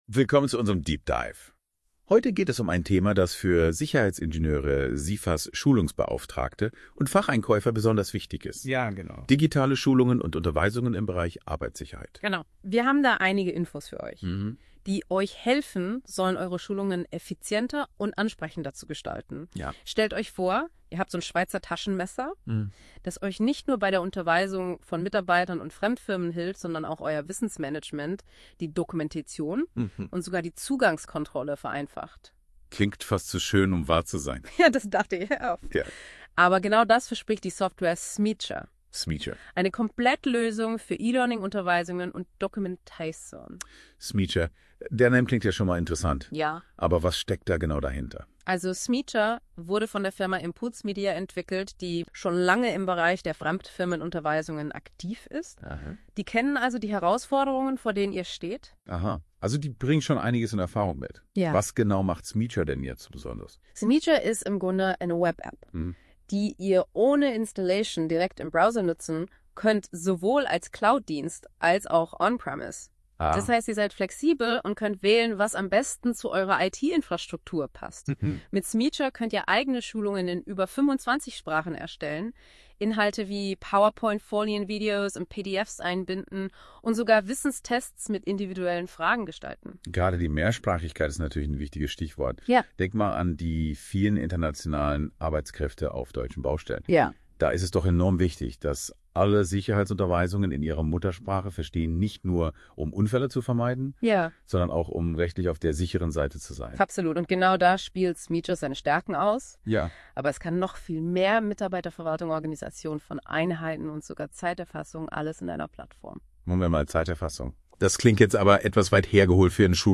Neu im KI-Podcast:
Erfahren Sie in unserem aktuellen KI-generierten Talk, wie Sie Koordinationsprobleme lösen und Zeitaufwand und Kosten reduzieren, während die Qualität, Nachvollziehbarkeit und Effizienz Ihrer Schulungen steigt.